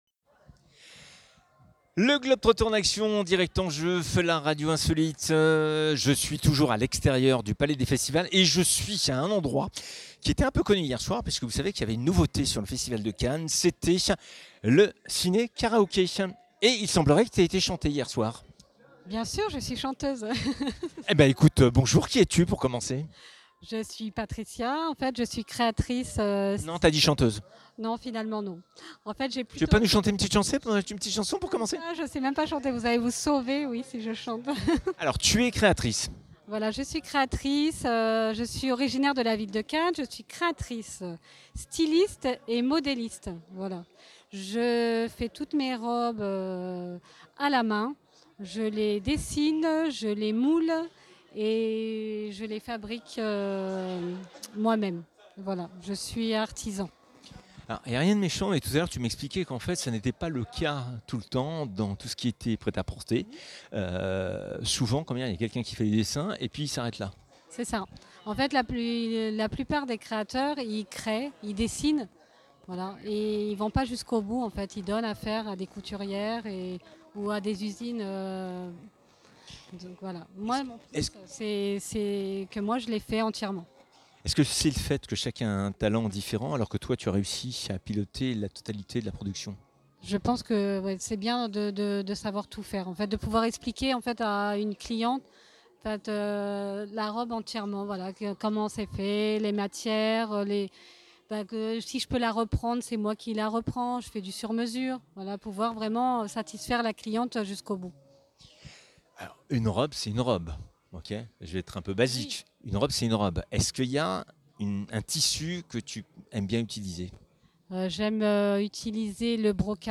La "Première" d'une très longue série d'interviews, de temps forts, d'Insolite avec une Vraie Créatrice de mode Cannoise